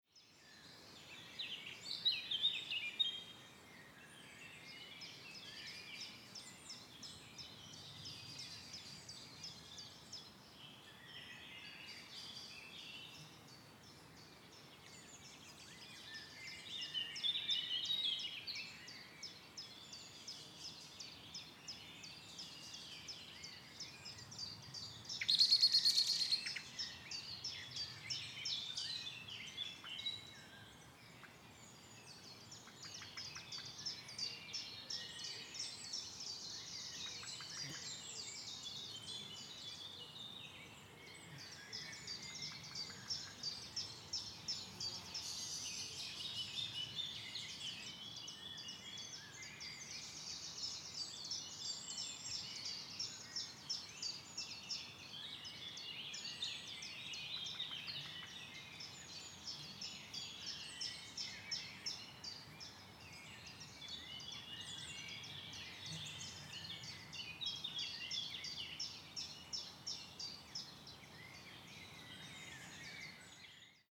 Captés avec le plus grand soin sur enregistreur Sound-Device et microphone Neuman double MS km 120-140, mastering protools 24b-48kHz ou 24b-96kHz.
wild sound